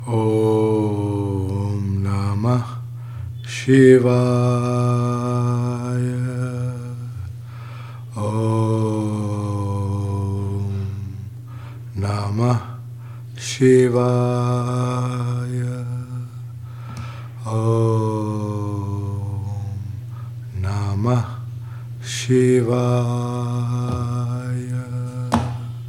Notice a long gap between two recitations of ‘namaḥ śivāya’. This is the inhalation of ‘a-u-m-nāda-bindu’. the omkara is a pañcākṣarī !
om-namah-shivaya.mp3